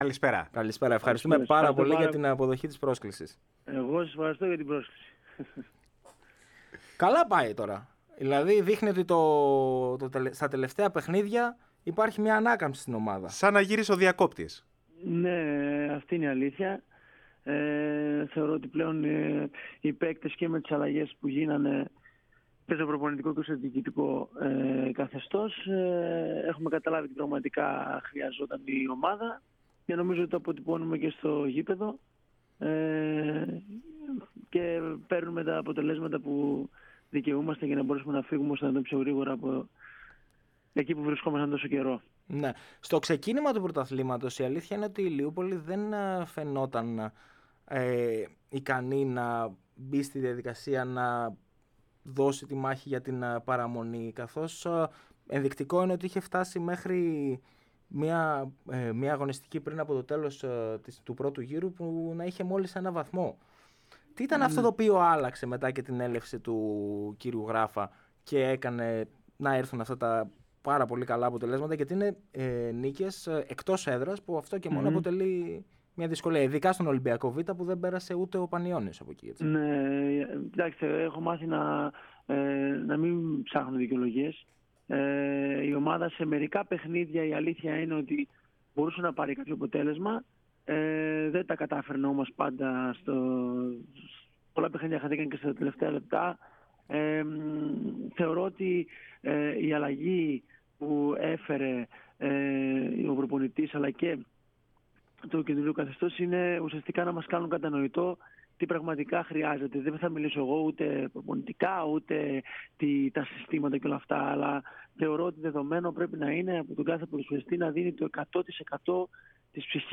στον αέρα της ΕΡΑ ΣΠΟΡ στην εκπομπή "Μπάλα Παντού"